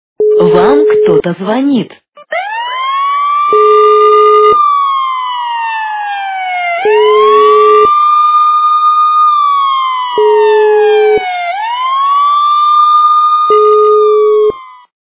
» Звуки » Смешные » Женский голос и сирена - Вам кто-то звонит
При прослушивании Женский голос и сирена - Вам кто-то звонит качество понижено и присутствуют гудки.
Звук Женский голос и сирена - Вам кто-то звонит